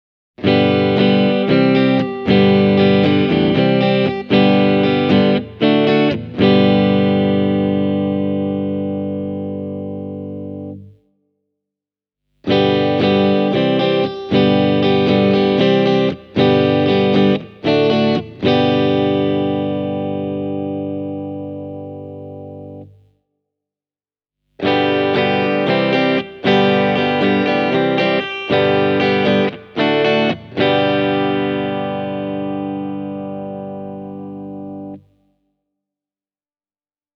A J.L.G. TeleGacy loaded with Mojotone’s ’59 PAF Clone Reverse Zebra humbuckers: